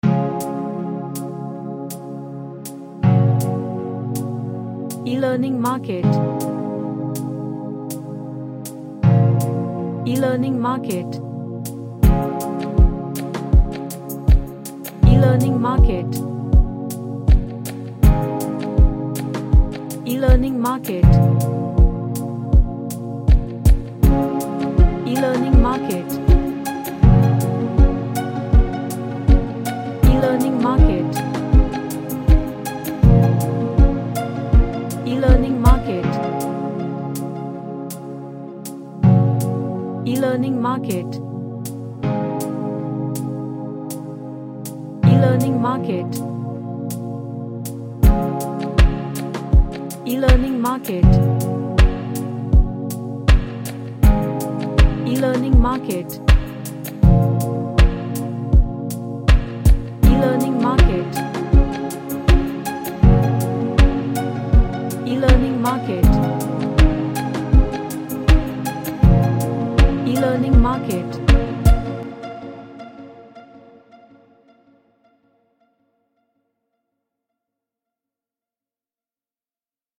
An arppegio track.
Relaxation / Meditation